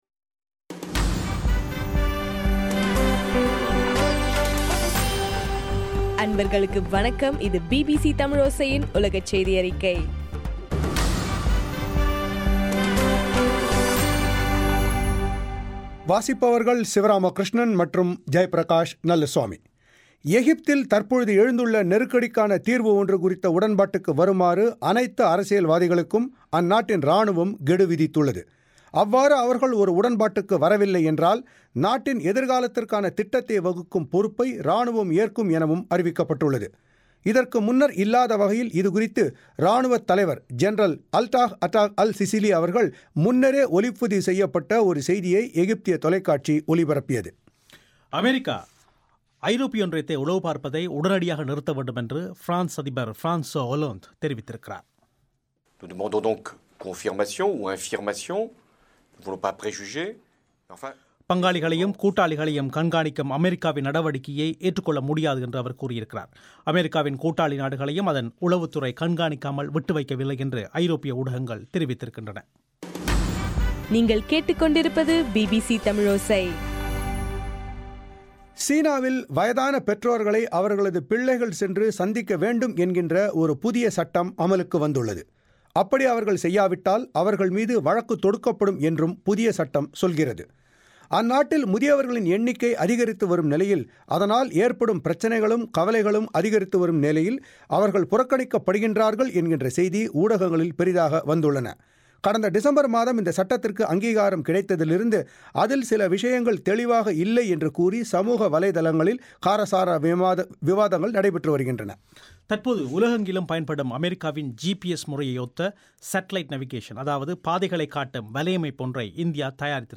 இலங்கையில் சக்தி எப்எம் வானொலியில் ஒலிபரப்பான பிபிசி தமிழோசையின் செய்தியறிக்கை